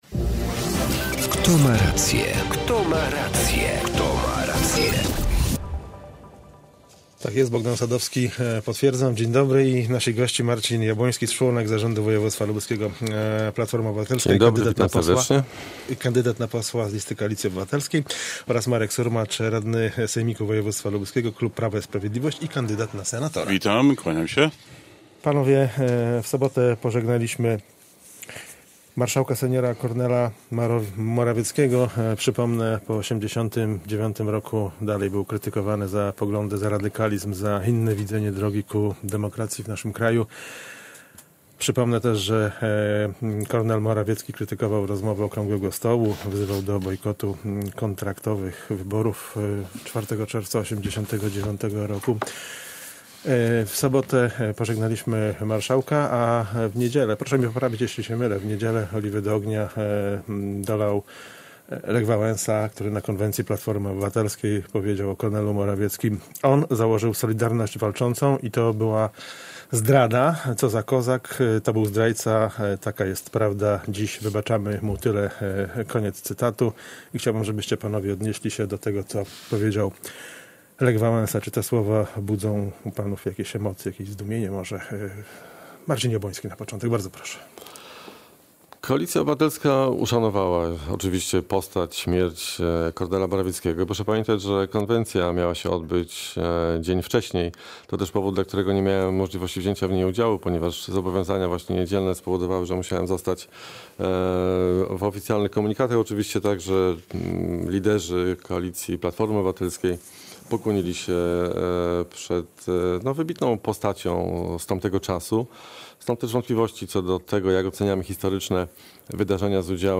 Spotkanie gości reprezentujących różne stanowiska, którzy konfrontują je w rozmowie jeden na jednego.